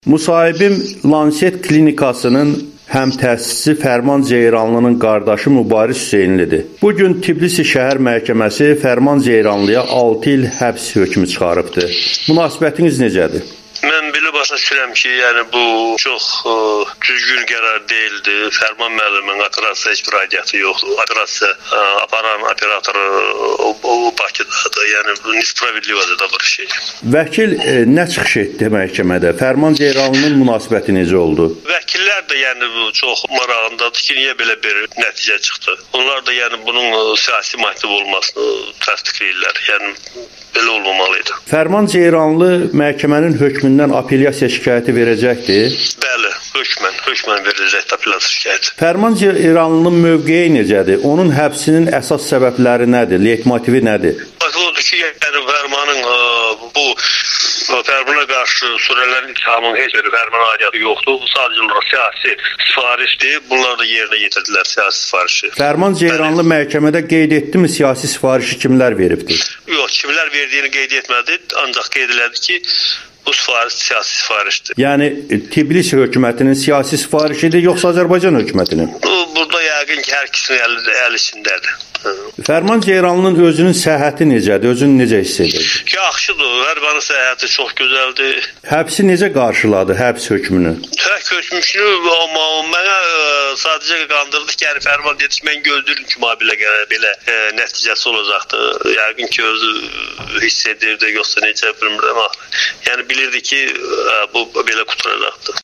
Amerikanın Səsinə müsahibəsi